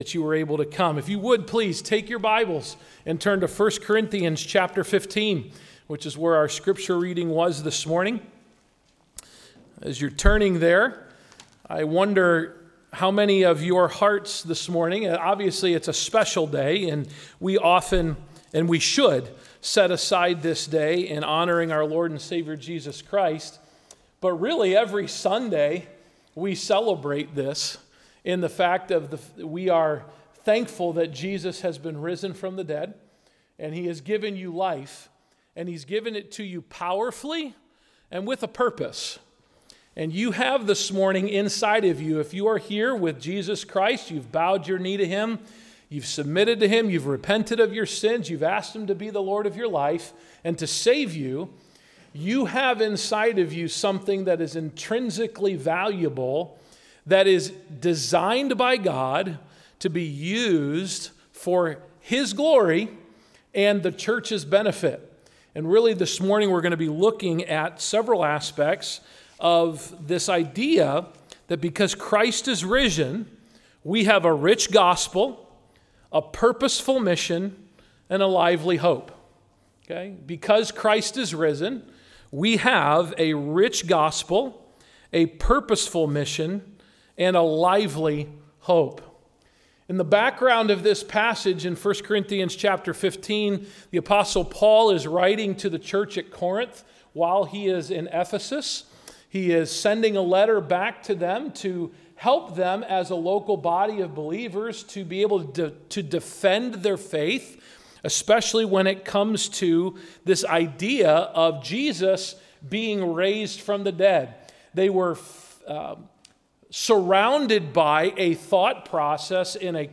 April-5-2026-AM-Service.mp3